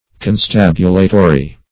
Constabulatory \Con*stab"u*la*to*ry\, n.